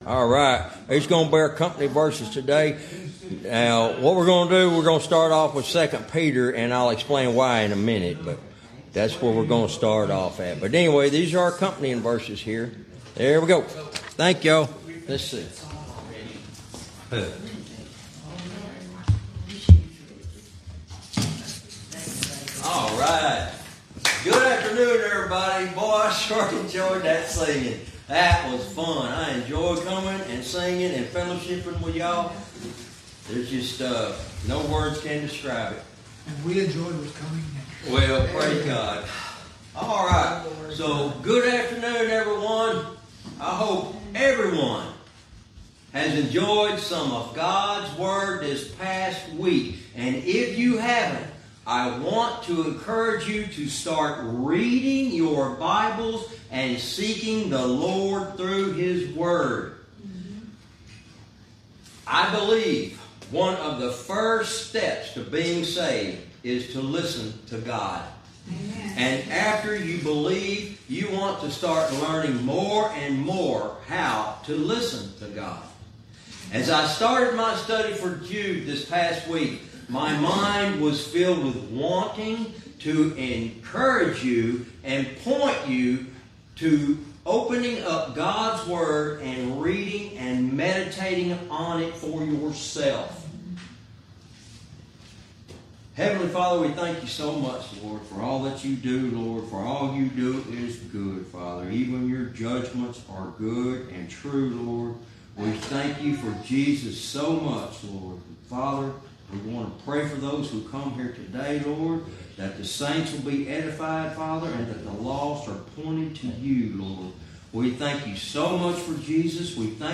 Verse by verse teaching - Jude lesson 72 verse 16